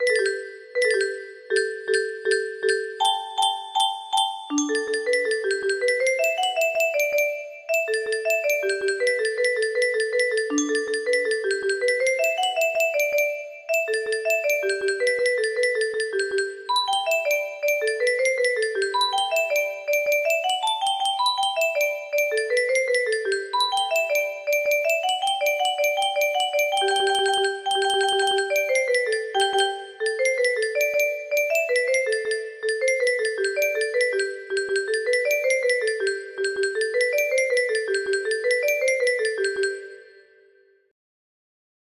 Can Can music box melody
Grand Illusions 30 (F scale)